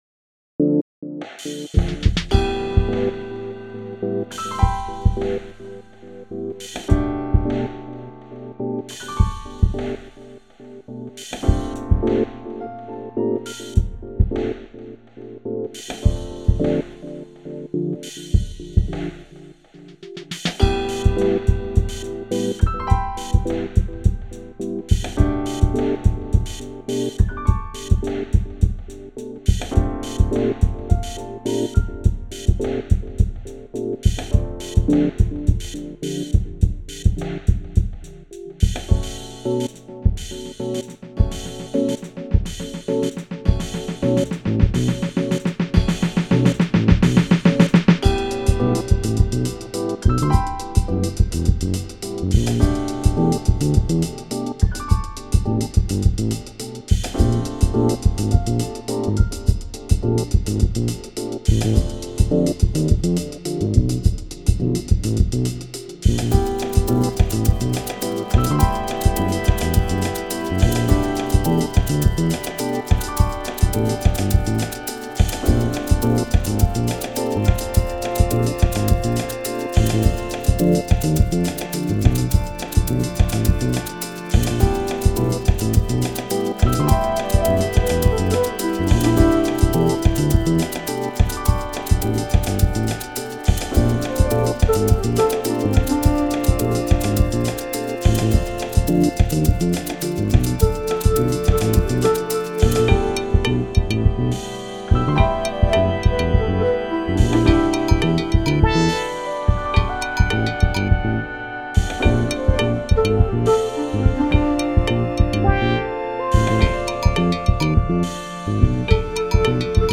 Home > Music > Jazz > Bright > Smooth > Dreamy